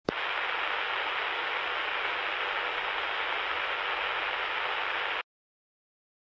Radio Static